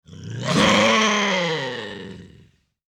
Bobcat Growl Bouton sonore
The Bobcat Growl sound button is a popular audio clip perfect for your soundboard, content creation, and entertainment.